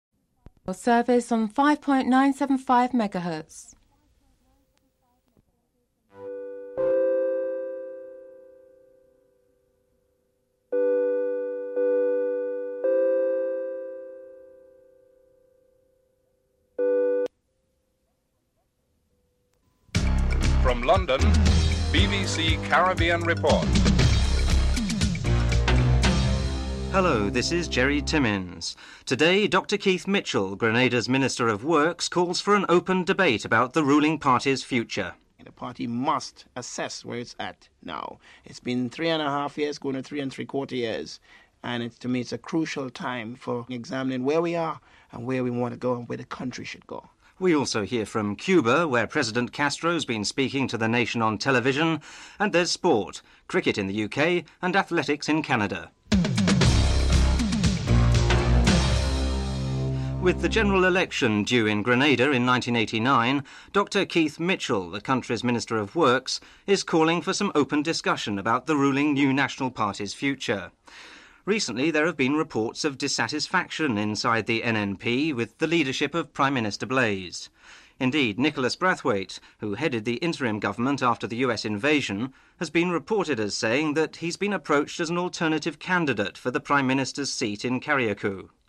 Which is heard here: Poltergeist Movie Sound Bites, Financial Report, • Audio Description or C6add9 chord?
Financial Report